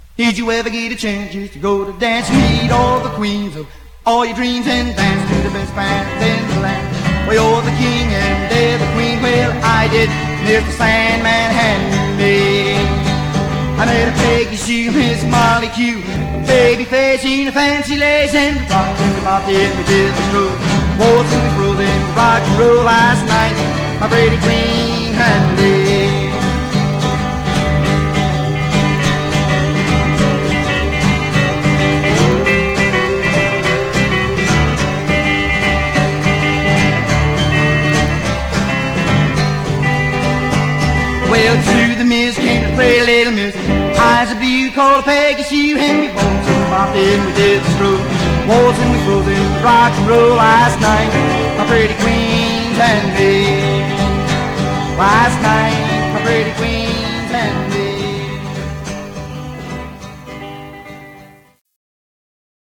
Some surface noise/wear
Mono
Rockabilly